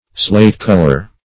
Slate-color \Slate"-col`or\